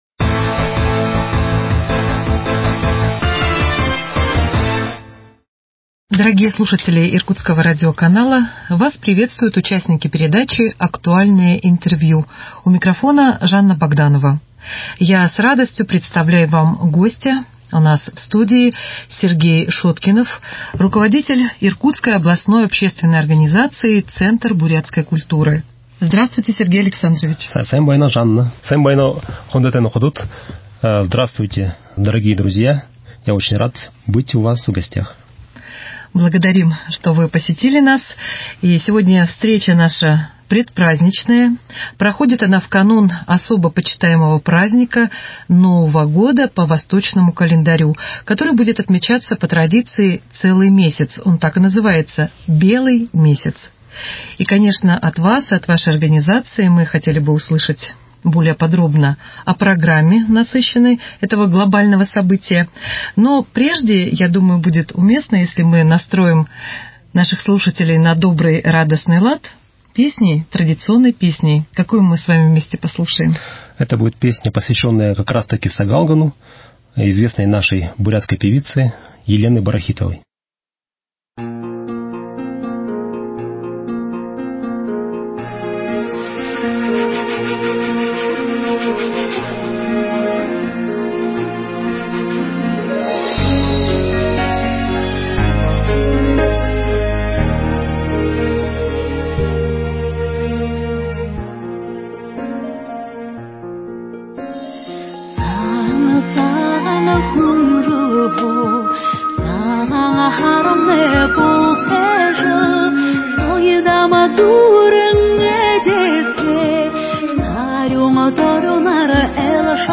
Актуальное интервью: Бурятский центр.